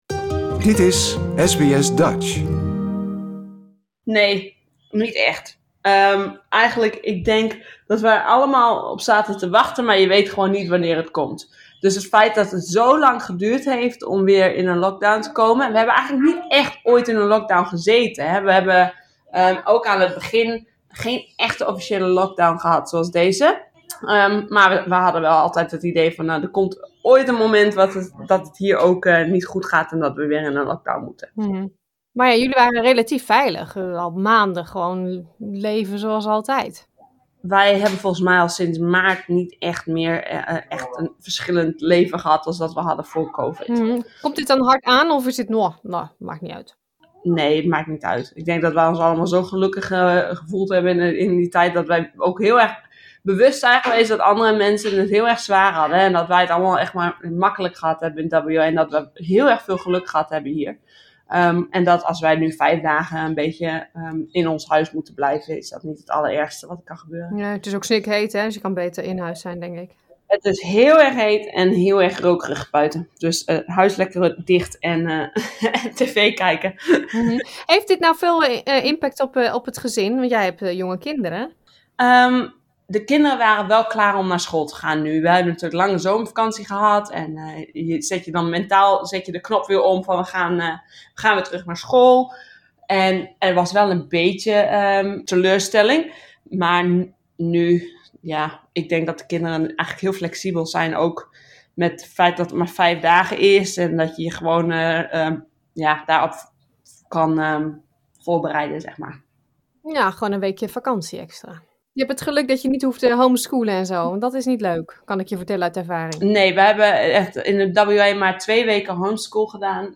We belden met enkele Nederlanders in WA om te horen hoe het ze vergaat.